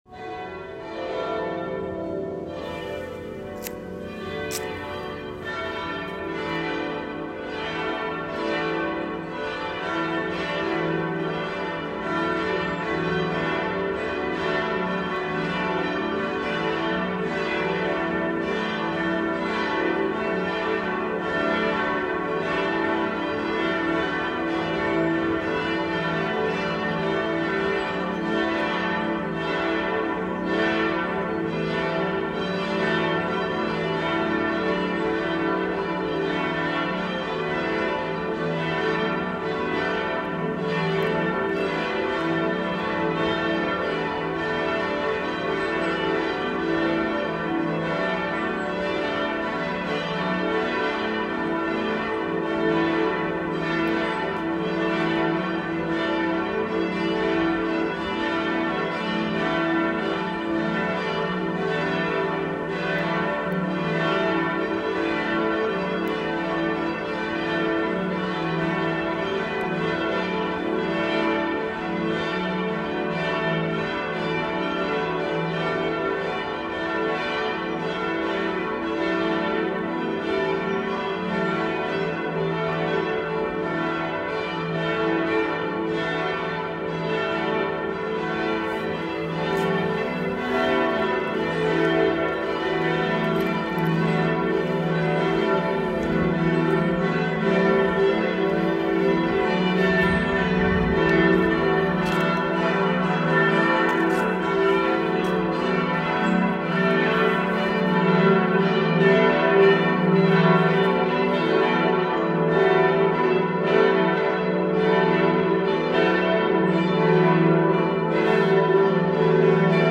Die Glocken der Schutzengelkirche können mit unterschiedlichen Geläutemotiven erklingen.
Die sehr gute Harmonie, die hohe Vibrationsenergie der Glocken in Zusammenwirkung der schönen Akustik der Glockenstube ergeben eine Klangwirkung von außerordentlicher Klarheit, Fülle und Beseelung.
Geläutemotiv Cibavit eos (zum Fronleichnamsfest):
Marien-Glocke, Joseph-Glocke, Schutzengel-Glocke, Antonius-Glocke